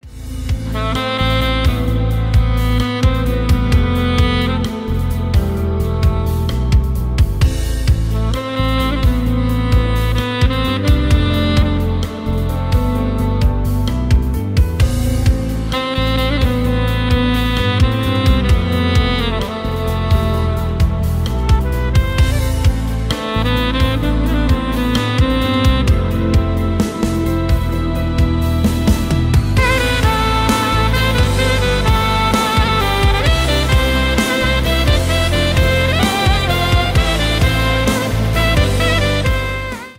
Поп Музыка
без слов